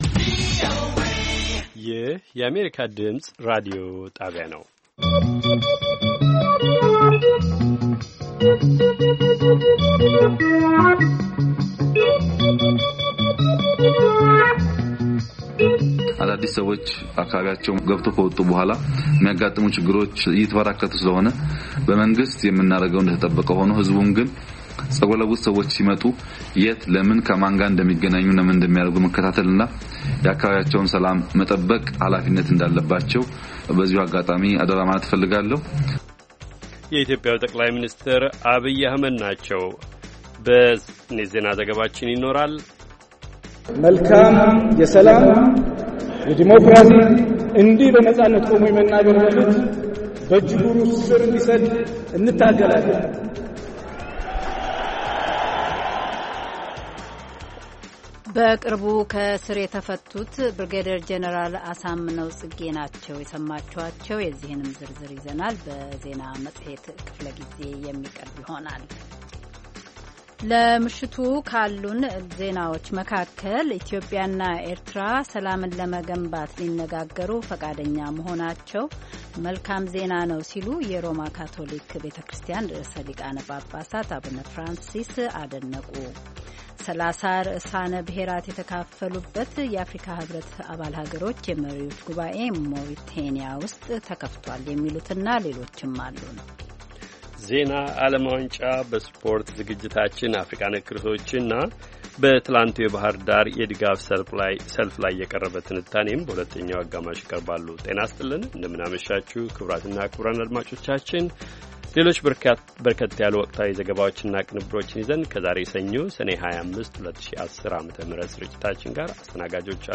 ሰኞ፡-ከምሽቱ ሦስት ሰዓት የአማርኛ ዜና
ቪኦኤ በየዕለቱ ከምሽቱ 3 ሰዓት በኢትዮጵያ አቆጣጠር ጀምሮ በአማርኛ፣ በአጭር ሞገድ 22፣ 25 እና 31 ሜትር ባንድ የ60 ደቂቃ ሥርጭቱ ዜና፣ አበይት ዜናዎች ትንታኔና ሌሎችም ወቅታዊ መረጃዎችን የያዙ ፕሮግራሞች ያስተላልፋል። ሰኞ፡- ስፖርት፣ አፍሪካ ነክ ርዕሶች፣ ጤና